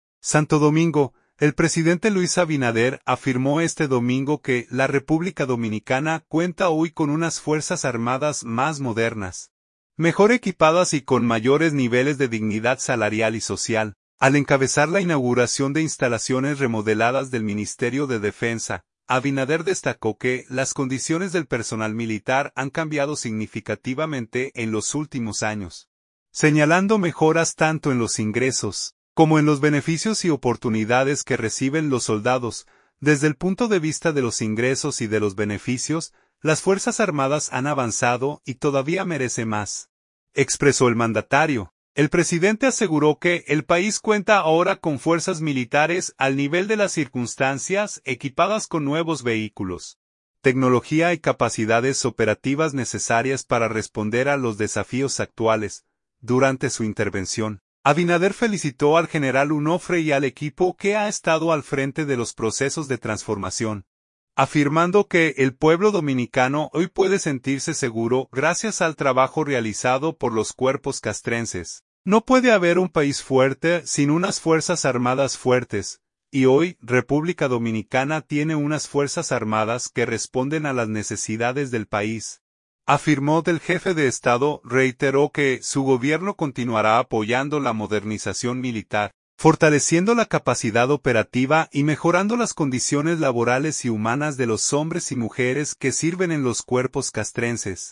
Santo Domingo. – El presidente Luis Abinader afirmó este domingo que la República Dominicana cuenta hoy con unas Fuerzas Armadas más modernas, mejor equipadas y con mayores niveles de dignidad salarial y social, al encabezar la inauguración de instalaciones remodeladas del Ministerio de Defensa.